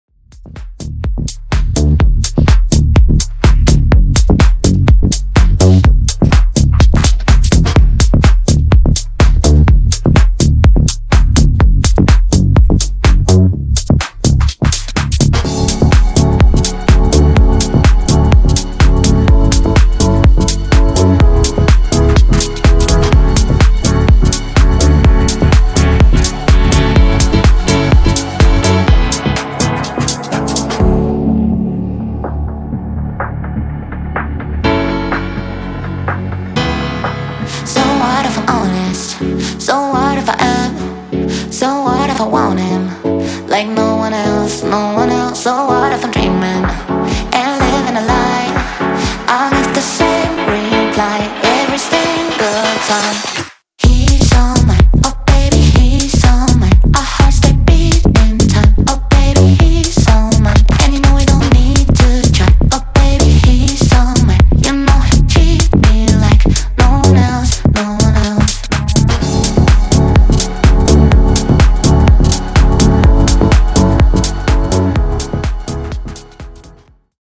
Genres: 2000's , RE-DRUM Version: Clean BPM: 97 Time